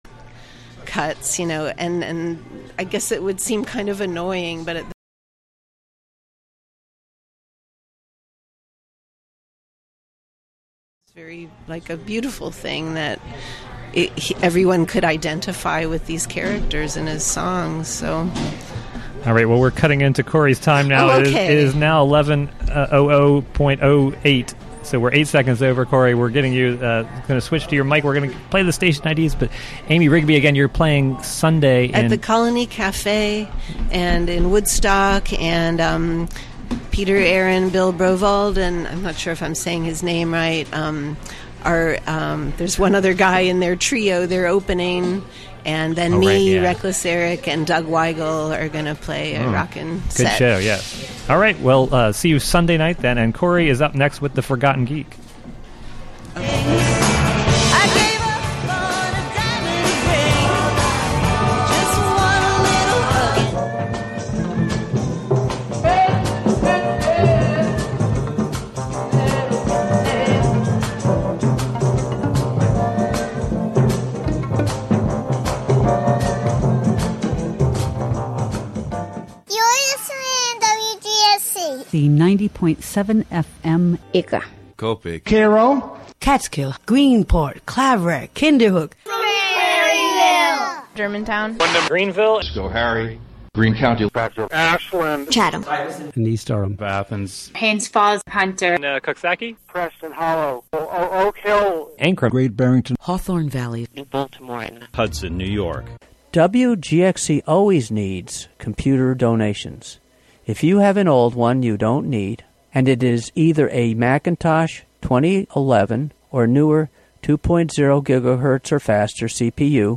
Broadcast live HiLo in Catskill.